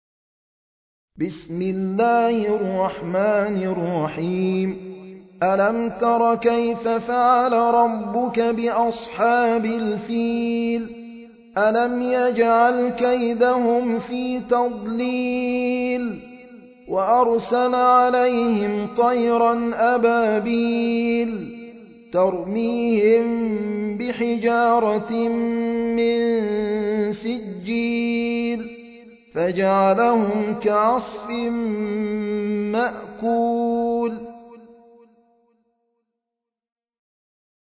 High Quality Quranic recitations and Islamic Lectures from selected scholars
المصحف المرتل - حفص عن عاصم - Al-Fil ( The Elephant )